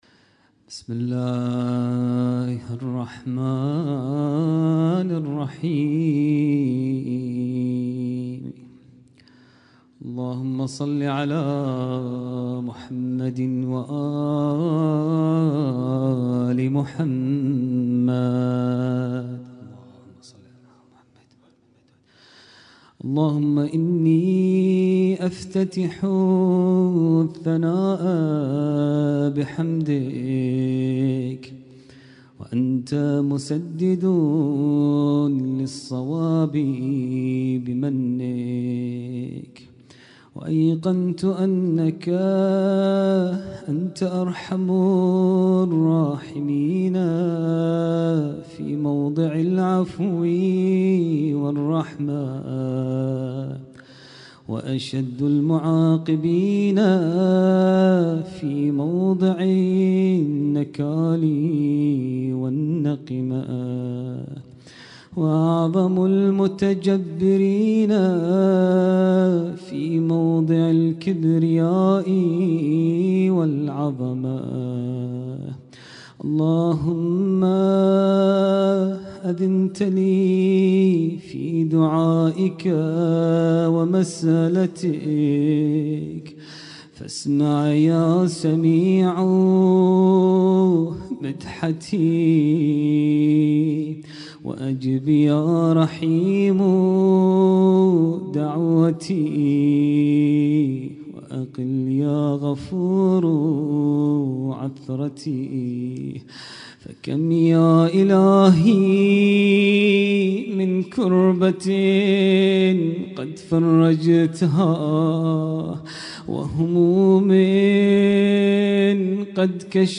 اسم التصنيف: المـكتبة الصــوتيه >> الادعية >> دعاء الافتتاح